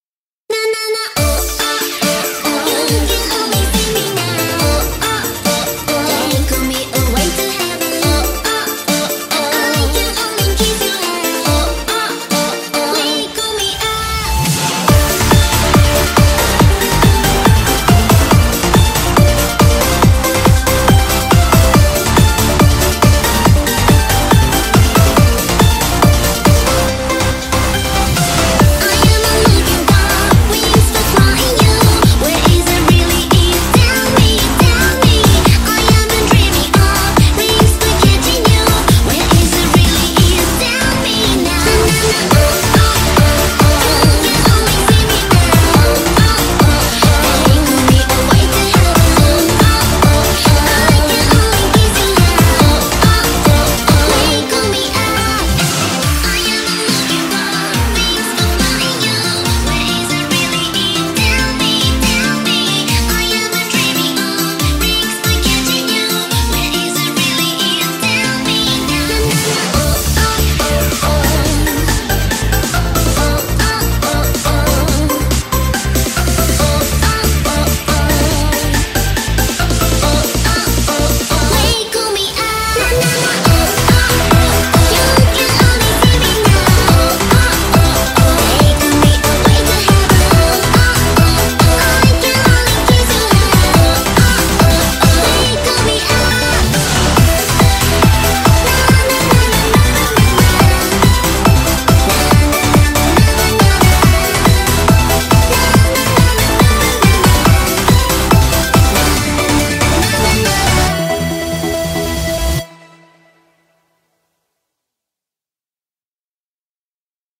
BPM140
Audio QualityPerfect (Low Quality)